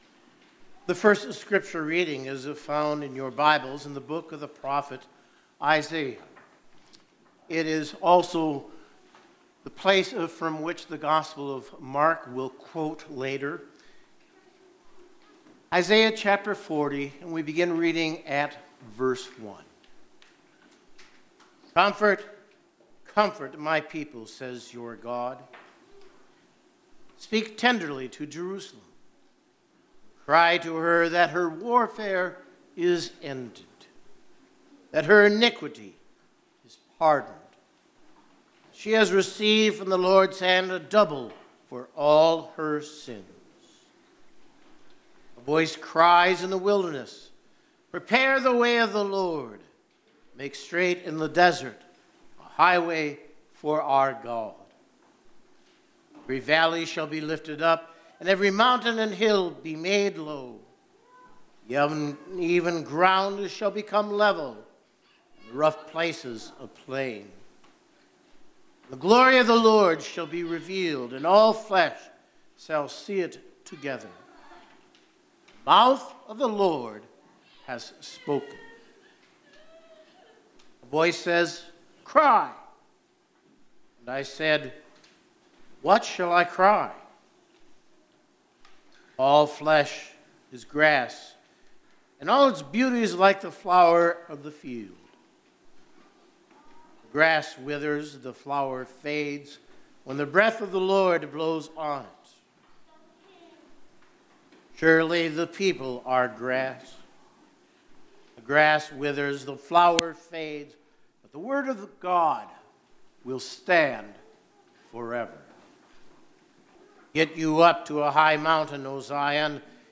Word & Sermon Weekly – Second Sunday in Advent – 12/06/2020